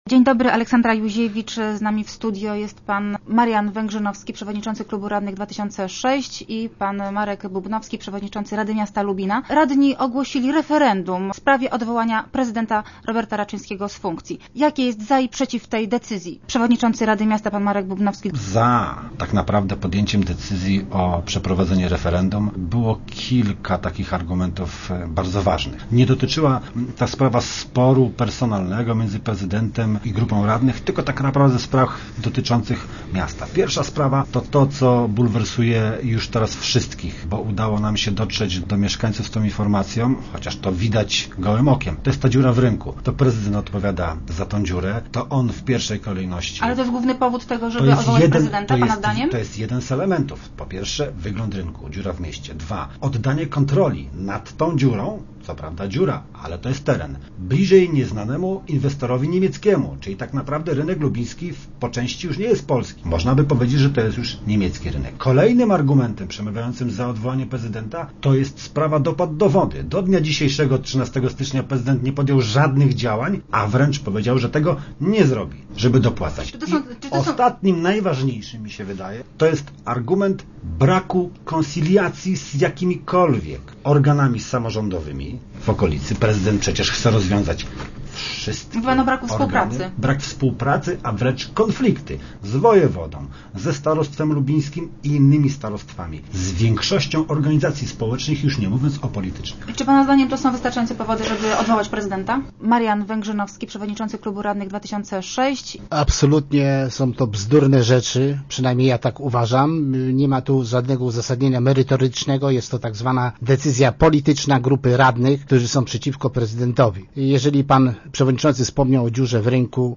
0917_bubnowski.jpg0917_wegrzynowski.jpg Komisarz wyborczy ustalił datę referendum w sprawie odwołania prezydenta Lubina na dzień 25 października. O argumentach za i przeciw referendum mówili dzisiaj w Rozmowach Elki  przewodniczący rady Marek Bubnowski i Marian Węgrzynowski, przewodniczący klubu radnych Lubin 2006.